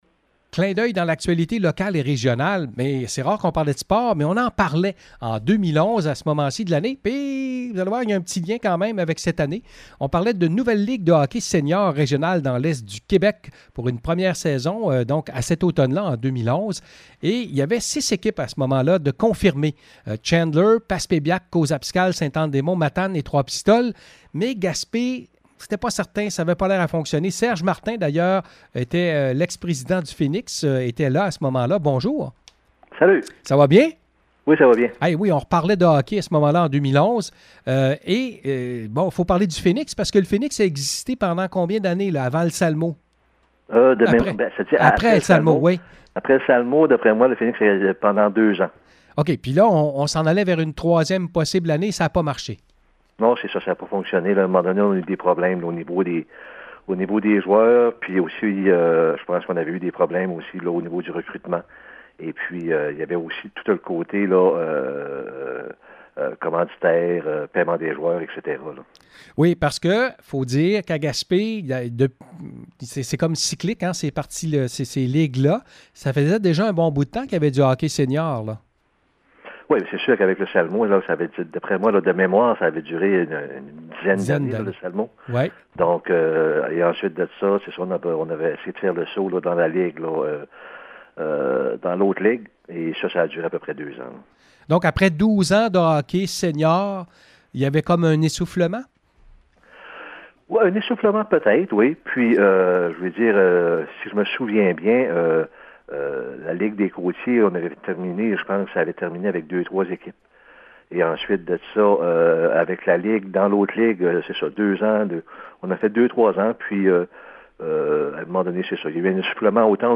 En 2011, une nouvelle ligue de hockey sénior voyait le jour, mais Gaspé n’a pas réussi à faire partie de cette dernière. Entrevue